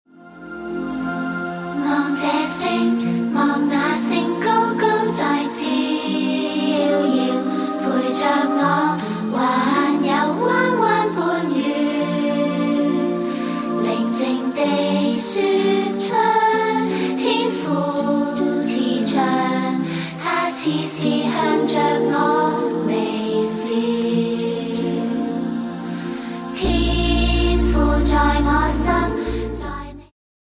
充滿動感和時代感
(有伴奏音樂版本)